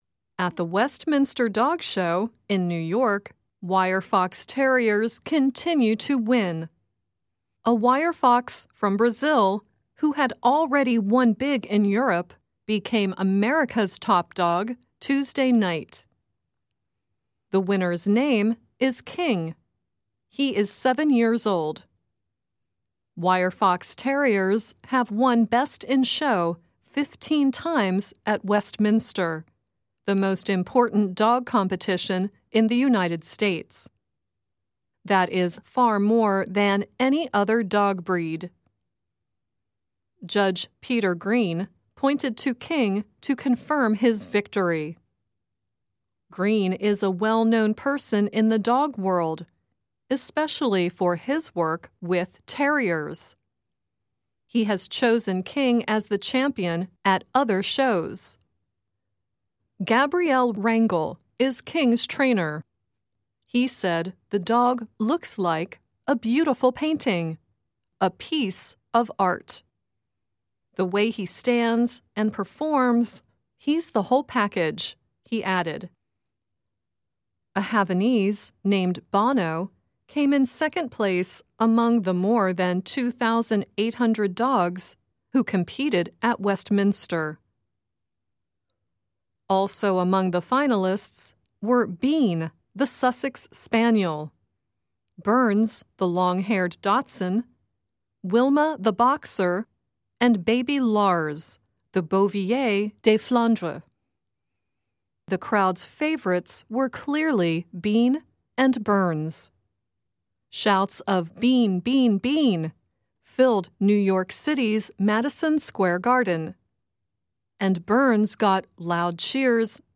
慢速英语:威斯敏斯特犬展有了一个新的“国王”